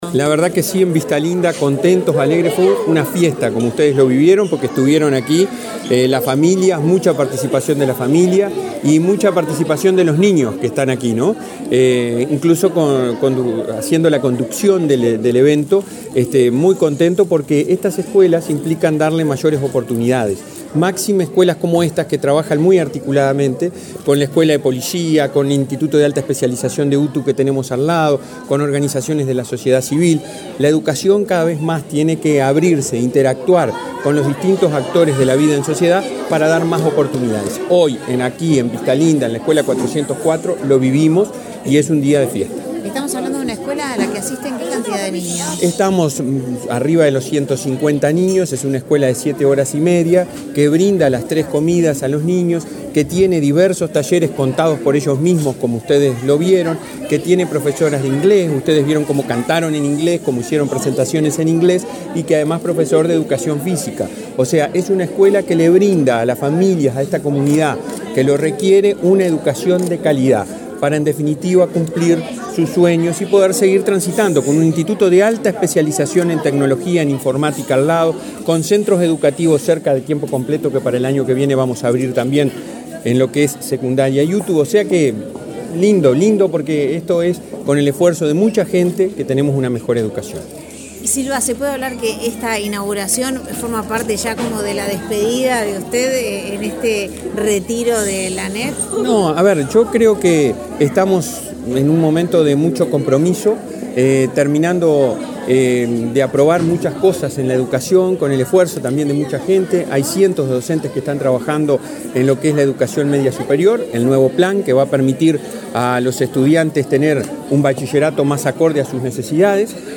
Declaraciones del presidente de la ANEP, Robert Silva
El presidente de la Administración Nacional de Educación Pública ( (ANEP), Robert Silva, participó de la inauguración de la escuela n.º 404 de tiempo